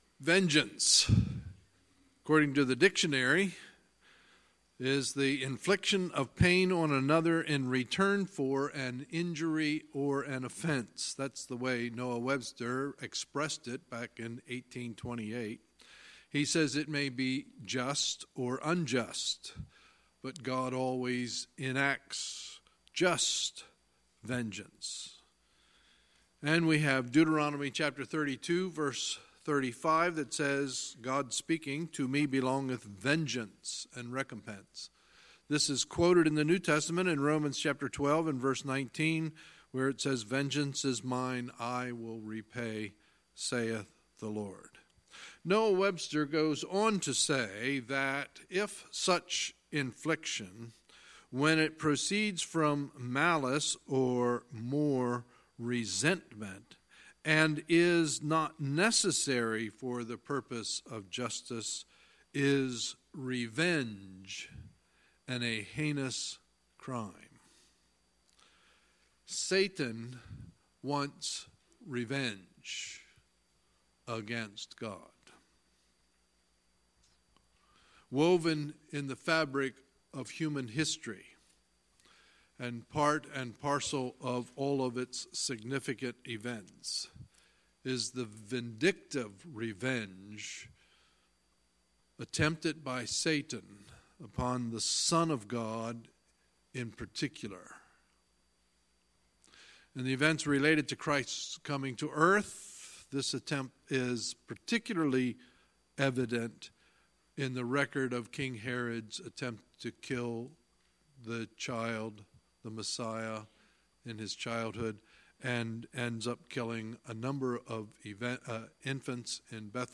Sunday, December 30, 2018 – Sunday Evening Service